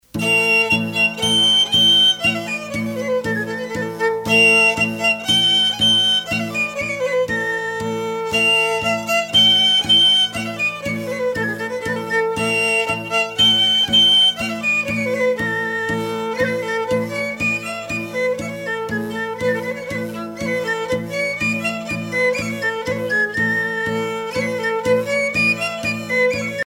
danse : peiroton ou pantalon (Gascogne)
les hautbois
Pièce musicale éditée